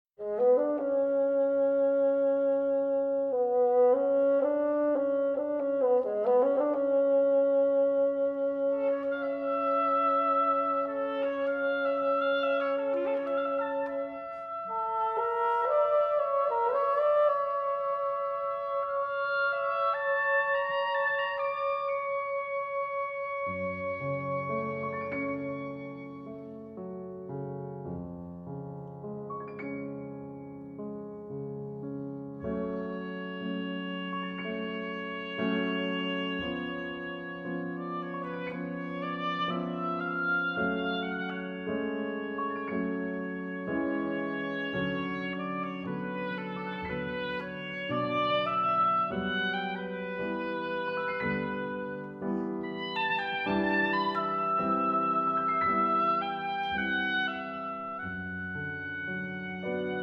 bassoon
Oboe